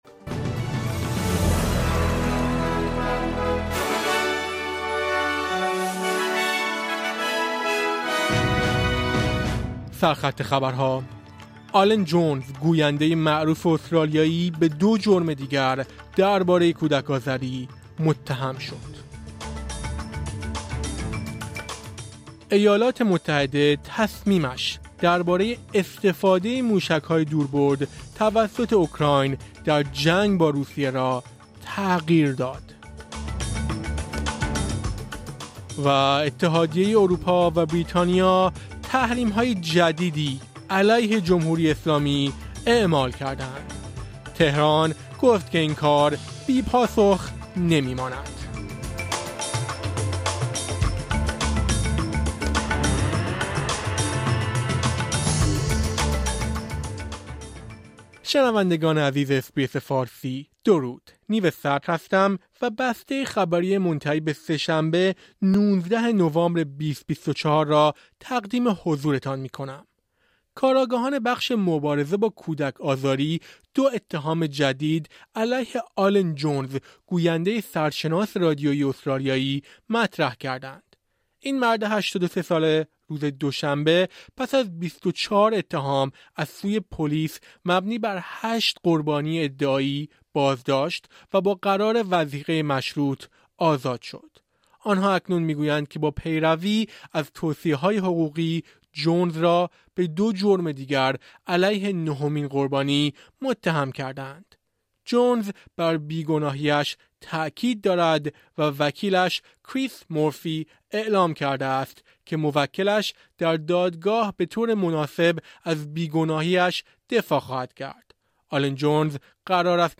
در این پادکست خبری مهمترین اخبار استرالیا، جهان و ایران در یک هفته منتهی به سه‌شنبه ۱۹ نوامبر ۲۰۲۴ ارائه شده است.